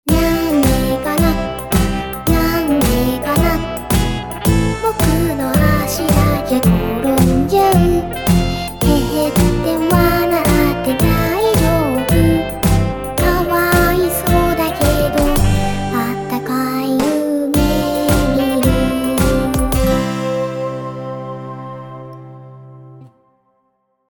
BPM: 110